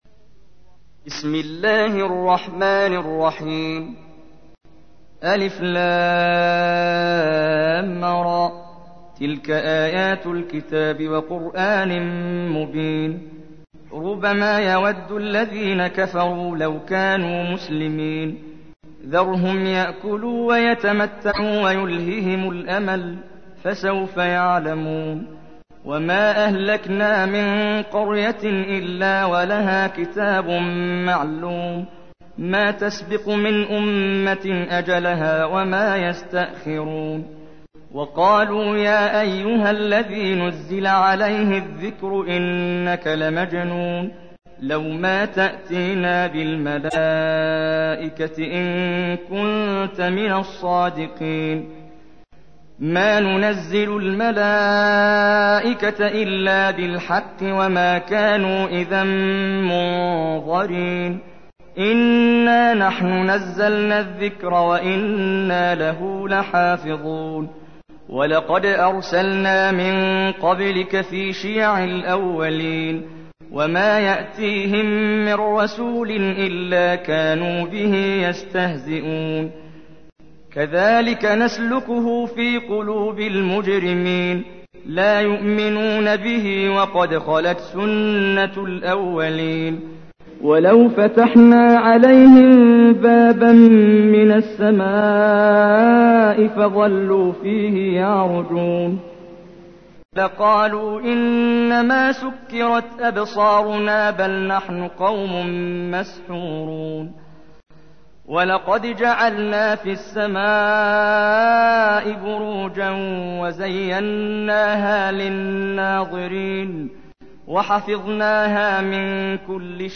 تحميل : 15. سورة الحجر / القارئ محمد جبريل / القرآن الكريم / موقع يا حسين